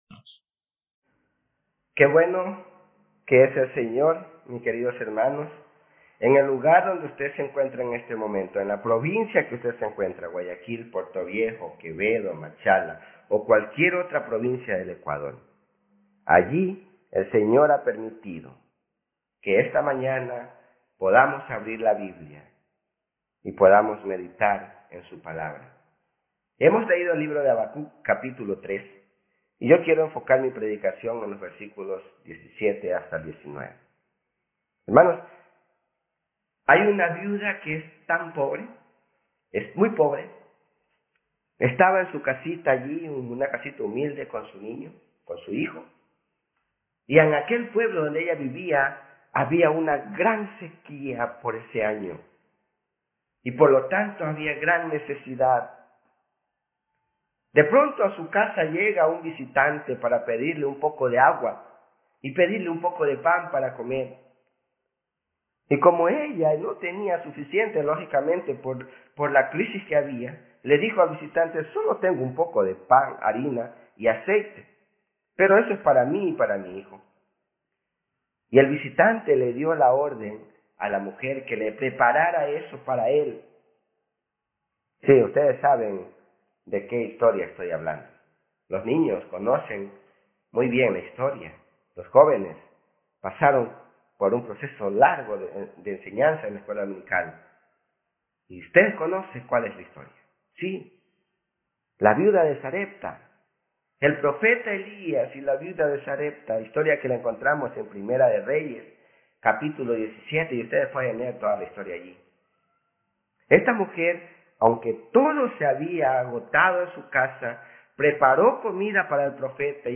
Habacuc 3 Tipo: Sermón Bible Text